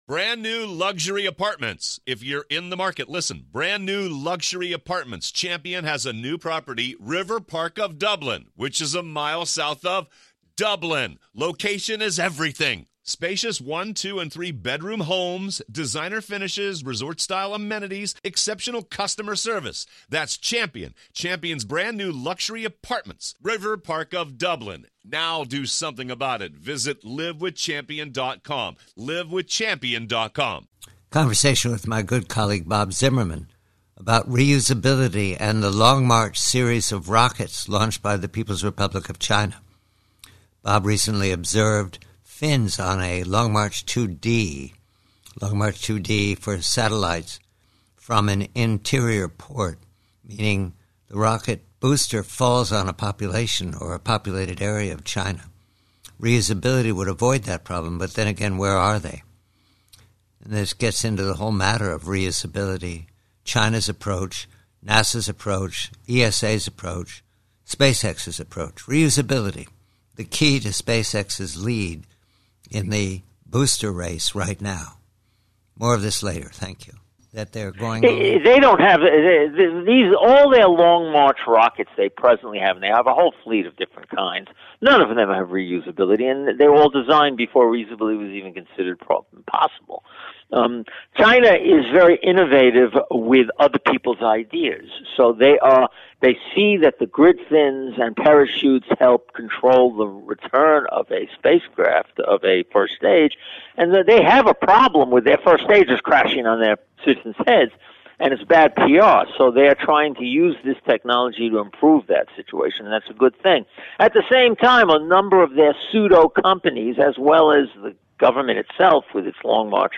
PREVIEW: BOOSTERS: Conversation